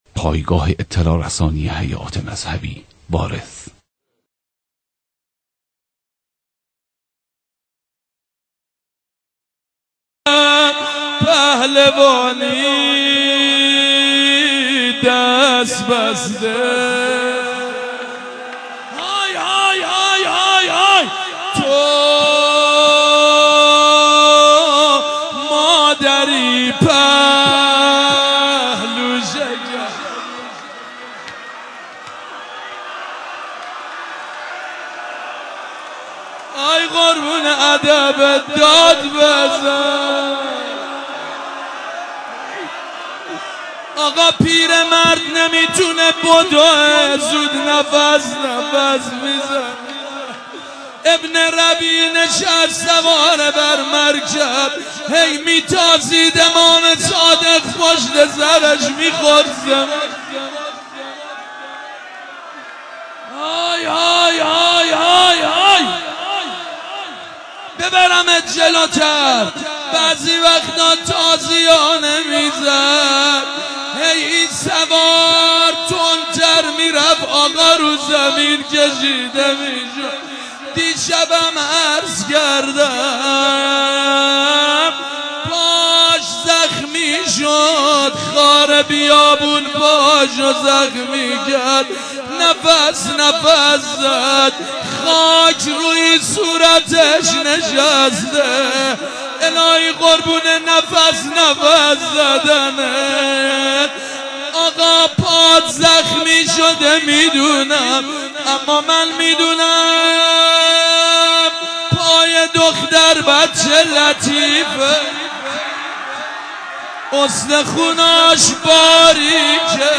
مداحی حاج سید مجید بنی فاطمه به مناسبت شهادت امام صادق (ع)